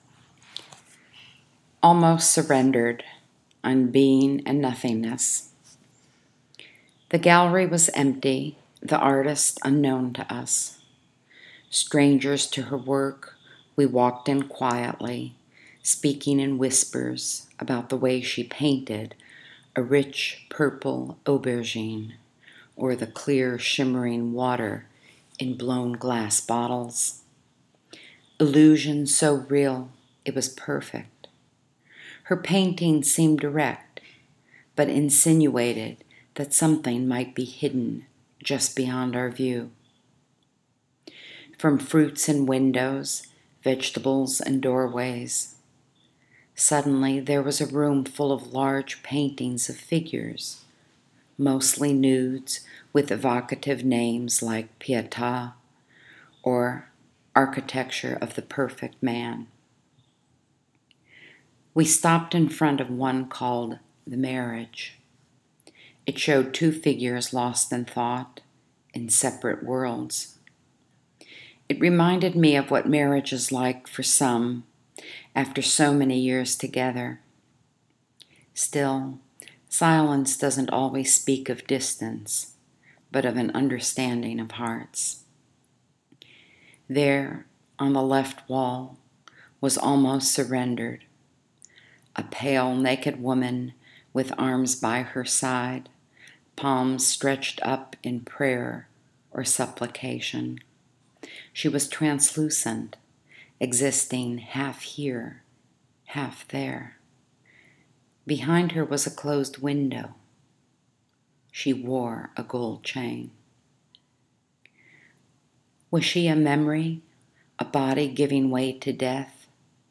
Click the link below if you’d like to hear me read this poem.